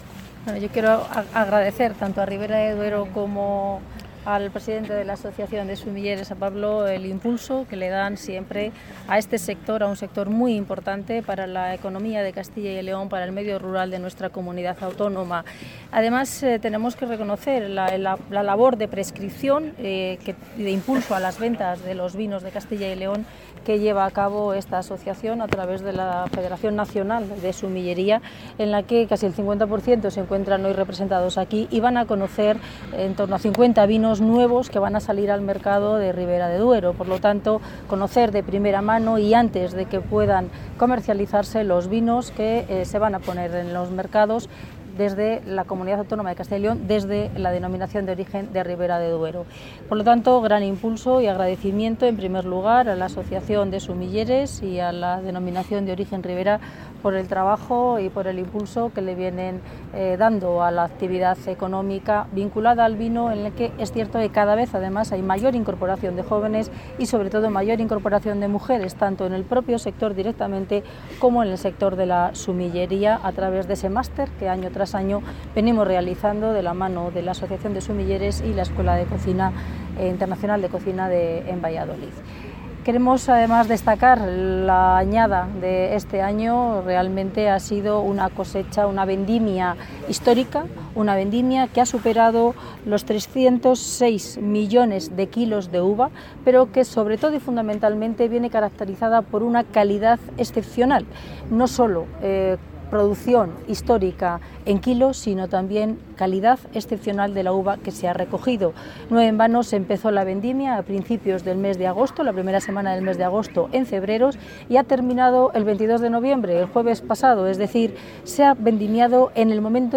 Declaraciones de la consejera de Agricultura y Ganadería.